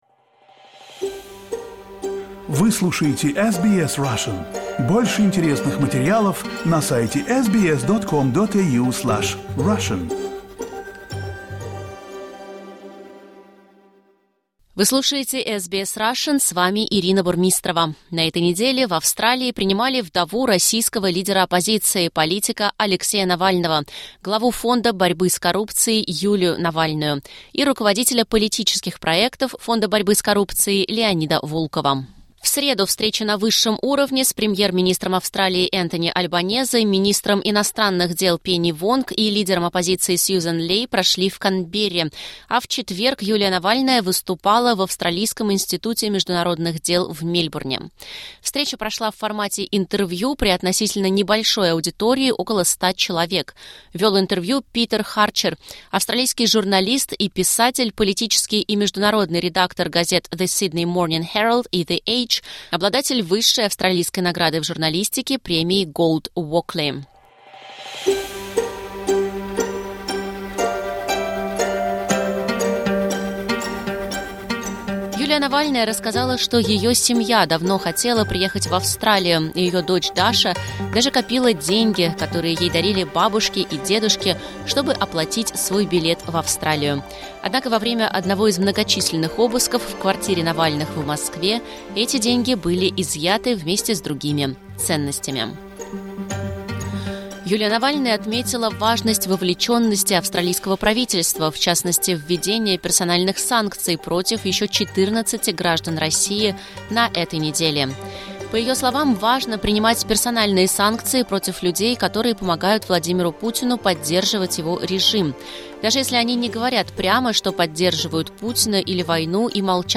как прошло выступление Юлии Навальной в Мельбурне